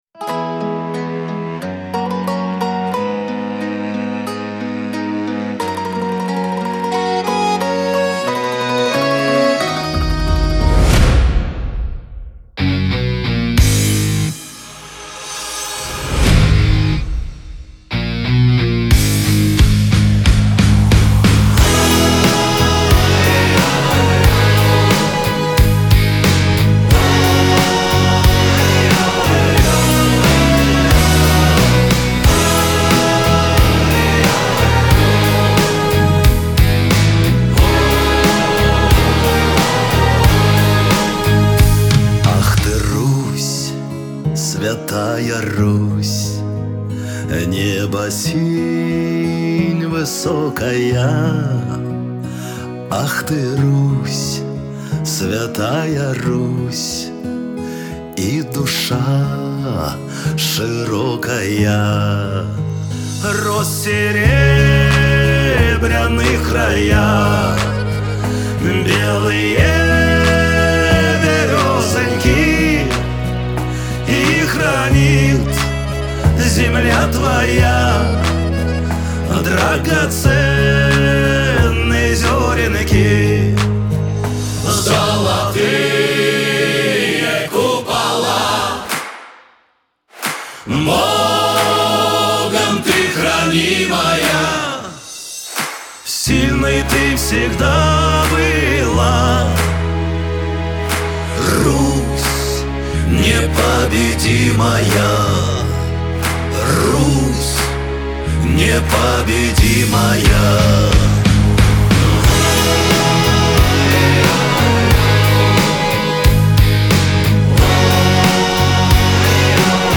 Лирика , эстрада
pop
диско